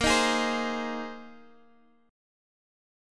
Bb9sus4 chord